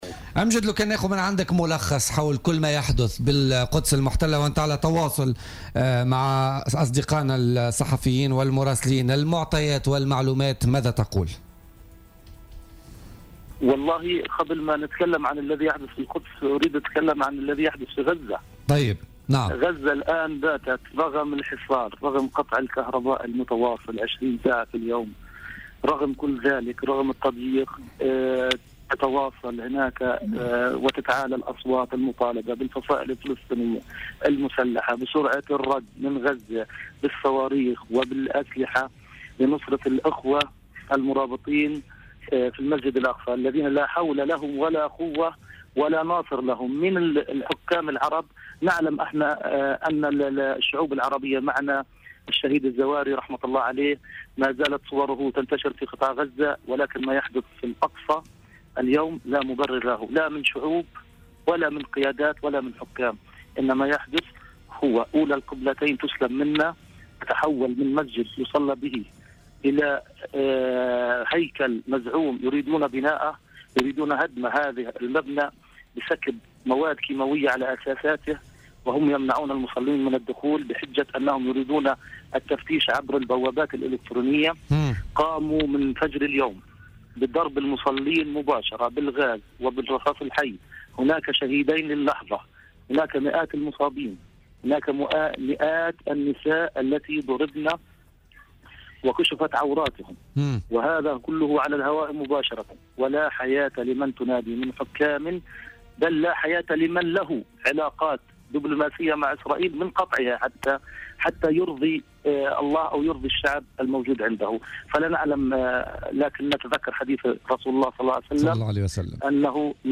وأضاف في مداخلة له اليوم في برنامج "بوليتيكا" : " أولى القبلتين تسلب منا ولا حياة لمن تنادي بالنسبة للحكام العرب".